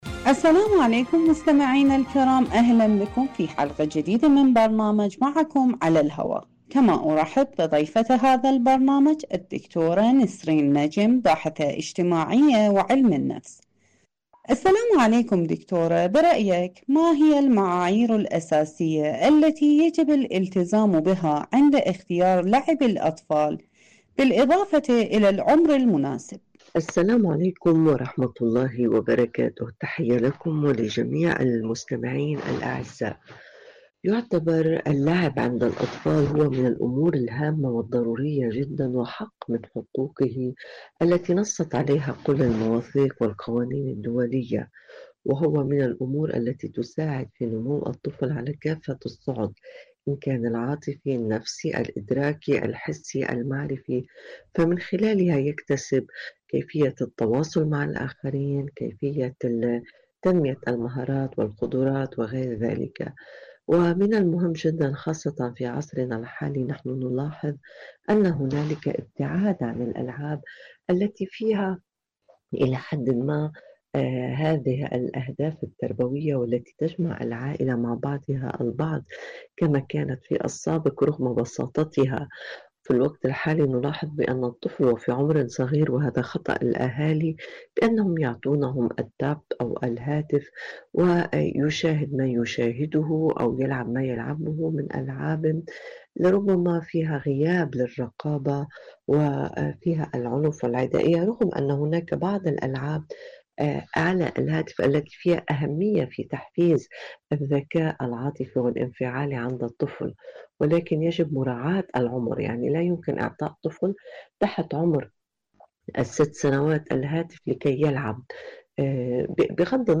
أي الألعاب نختار لأطفالنا؟.. مقابلة